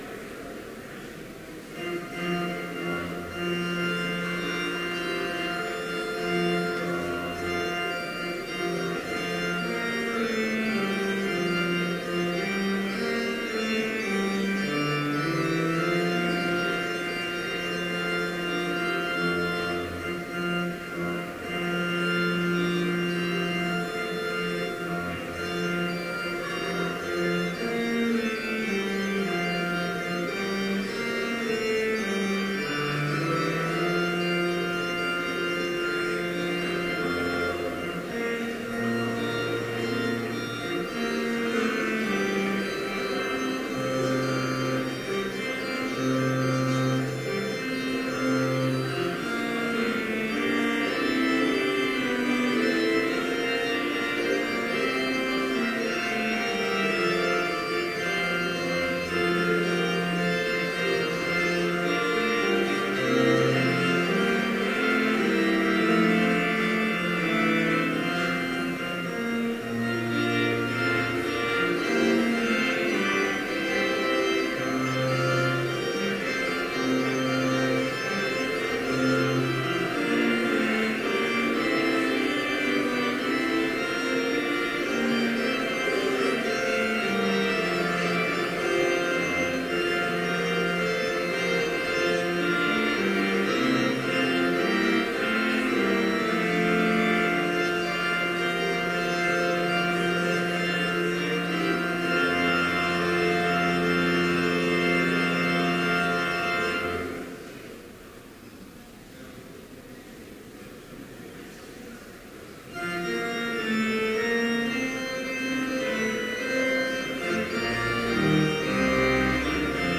Complete service audio for Chapel - April 30, 2015